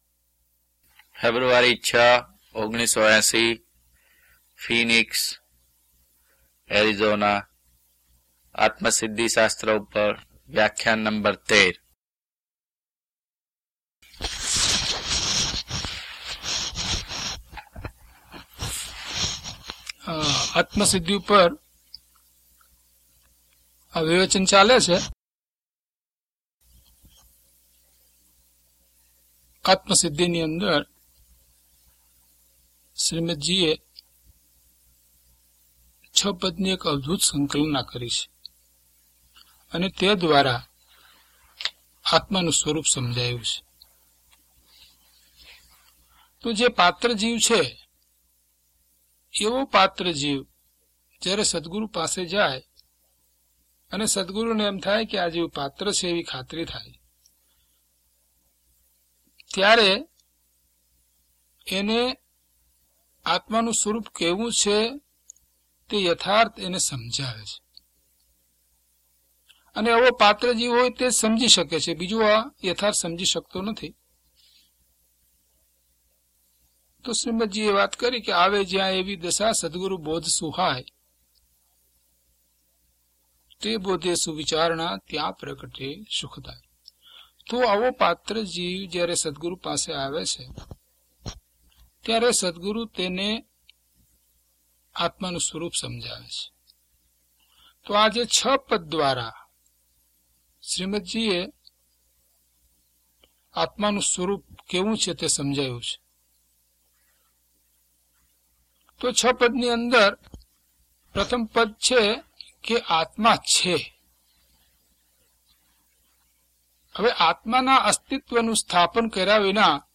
DHP022 Atmasiddhi Vivechan 13 - Pravachan.mp3